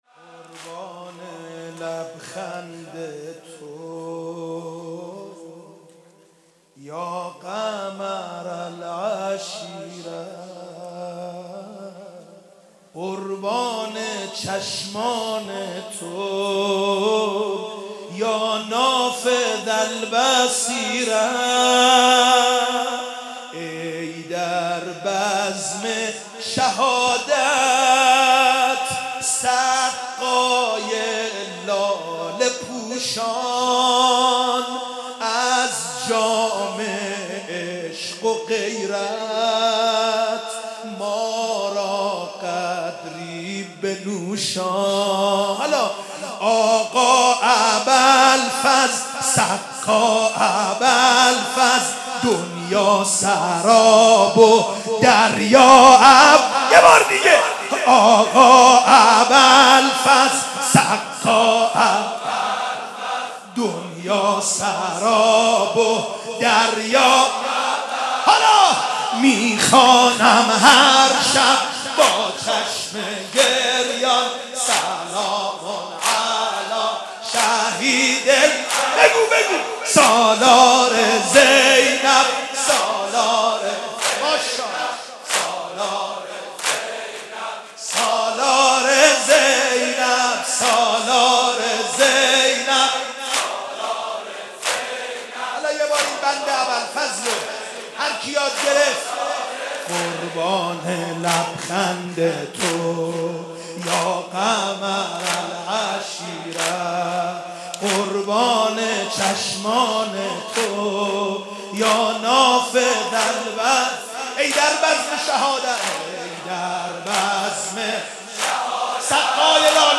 مقتل جانسوز حضرت قمر بنی هاشم(ع)
روضه/دشتی جانسوز حضرت عباس(ع)